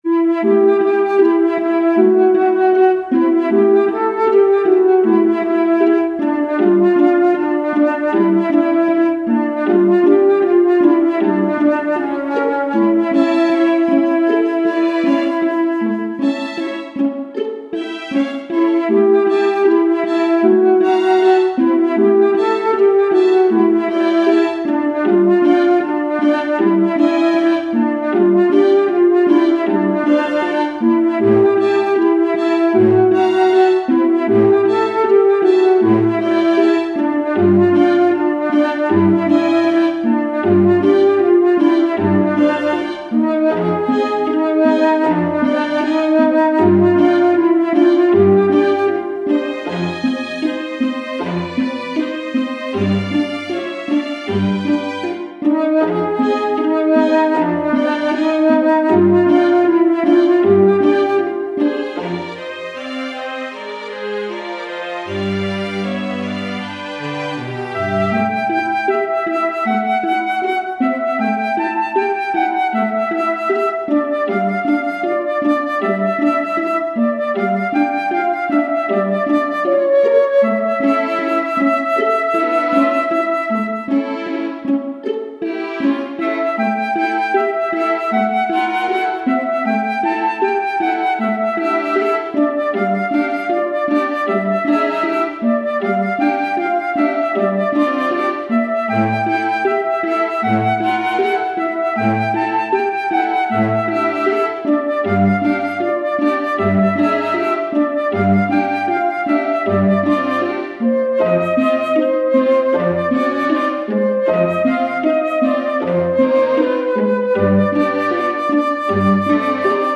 ogg(R) 陰うつ フルート 弦楽
悲しげに響くフルートの音色。